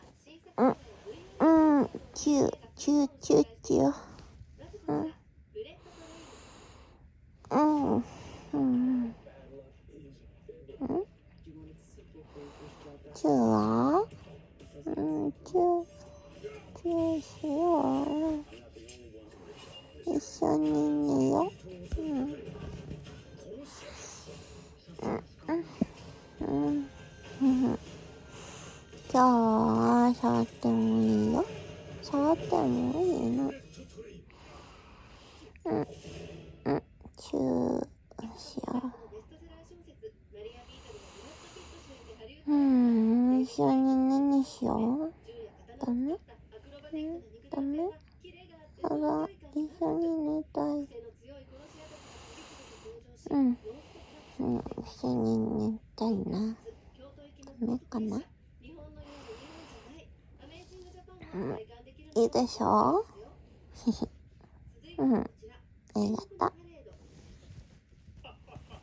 9/15酔っ払いビジホ甘えた音声